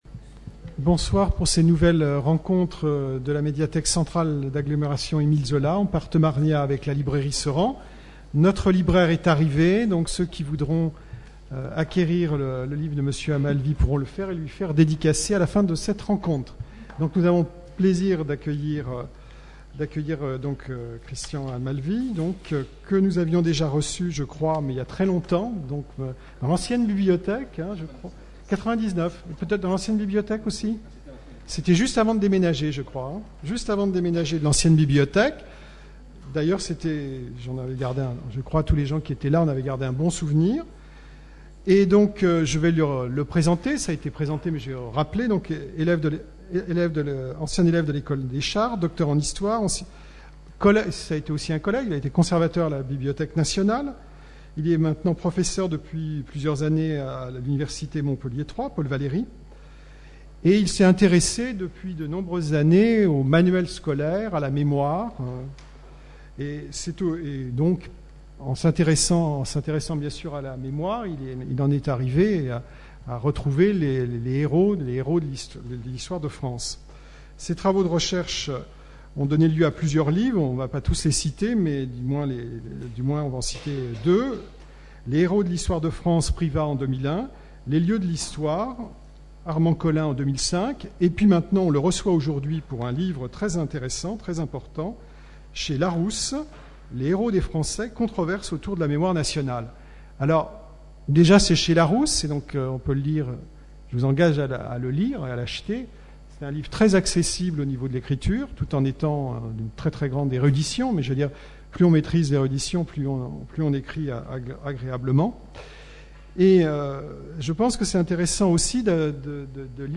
Conférences Rencontre littéraire